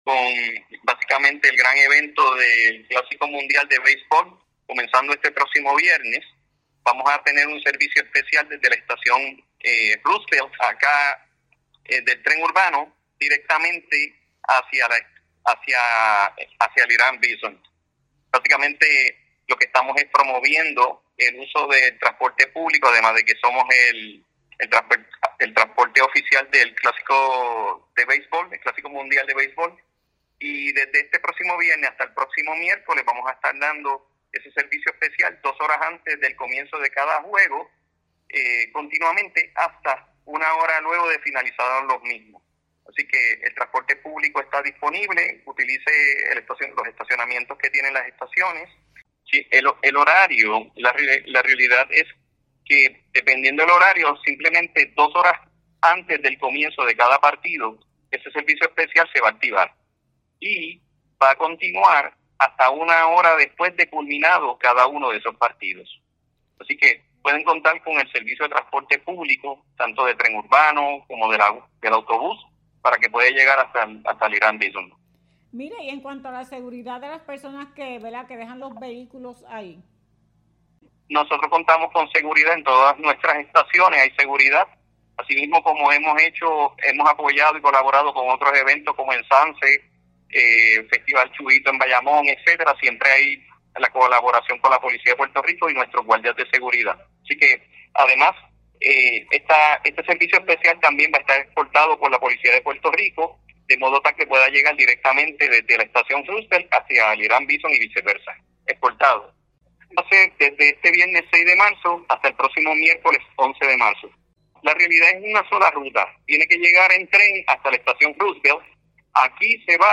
ENTREVISTA-ATI.mp3